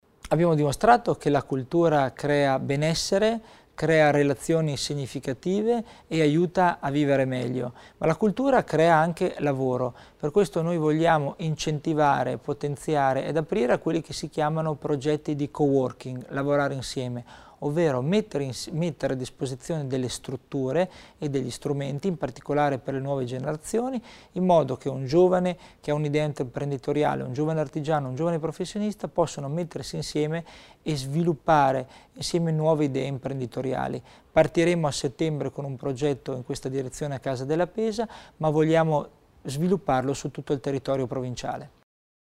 Creare lavoro con la cultura: questo l’obiettivo primario che l’assessore provinciale Christian Tommasini ha illustrato oggi (1° agosto) a Bolzano nei Colloqui di fine legislatura con i media affrontando il tema della politica culturale dei prossimi anni. Tra i nuovi strumenti per vivere grazie all'investimento in cultura Tommasini ha annunciato il coworking e i bandi per percorsi di impresa.